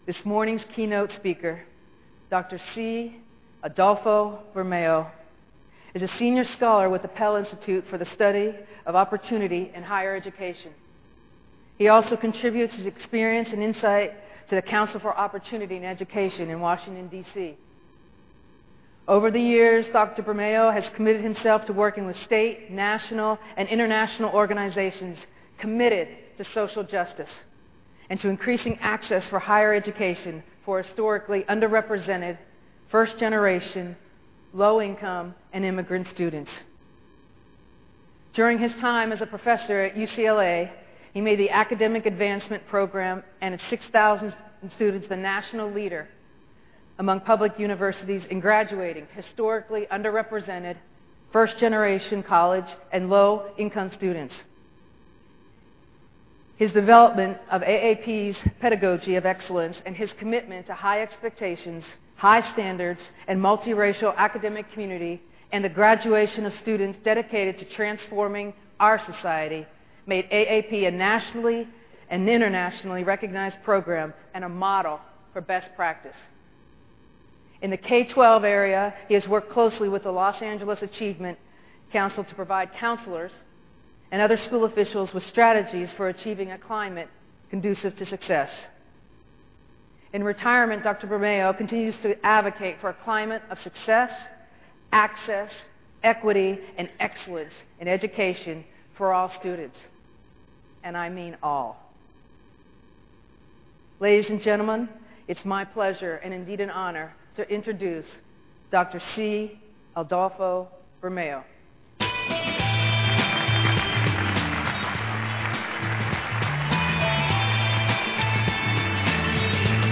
2009 Conference Keynote Addresses